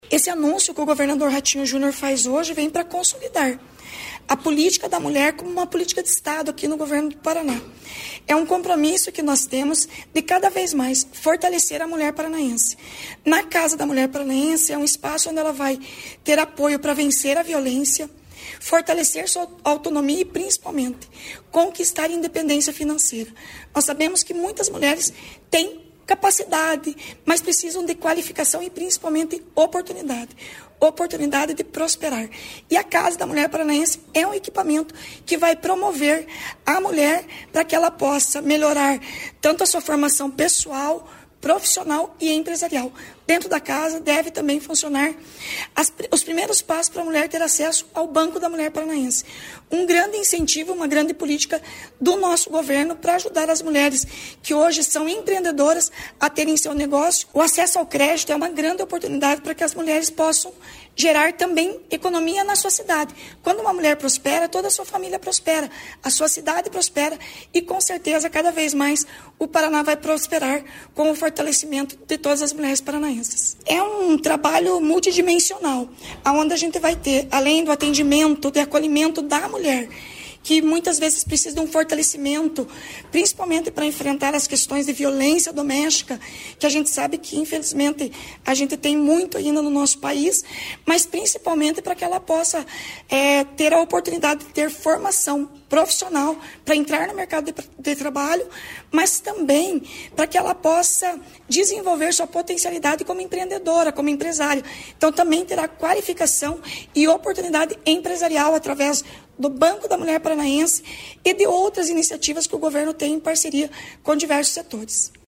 Sonora da secretária Estadual da Mulher, Igualdade Racial e Pessoa Idosa, Leandre dal Ponte, sobre as 30 novas Casas da Mulher Paranaense